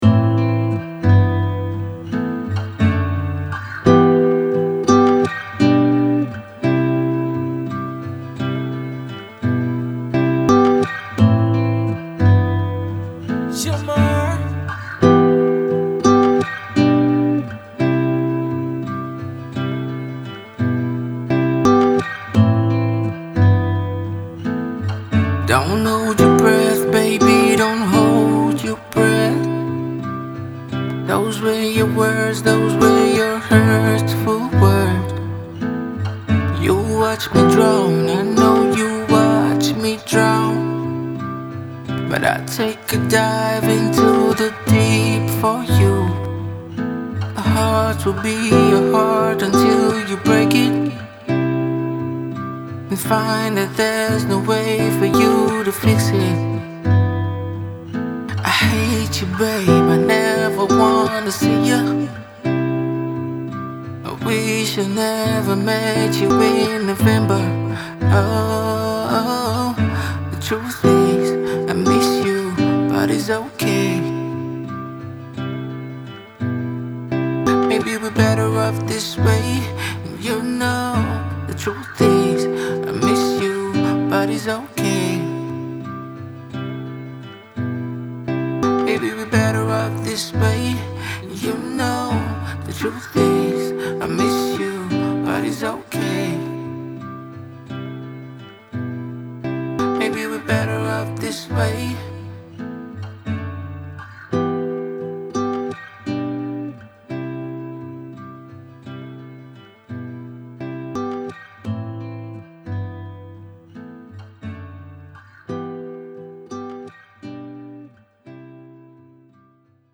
Soul
a Sombre song about A Heartbreak